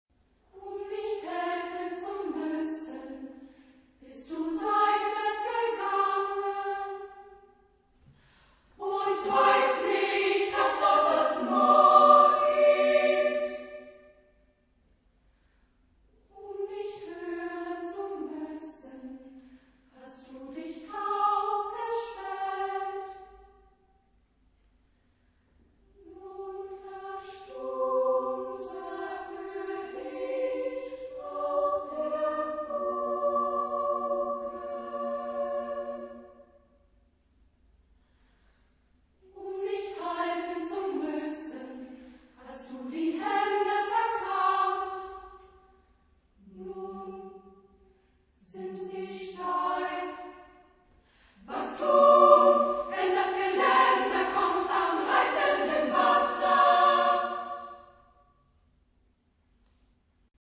Genre-Style-Forme : Pièce chorale ; Cycle ; Profane
Type de choeur : SSA  (3 voix égales de femmes )
Tonalité : libre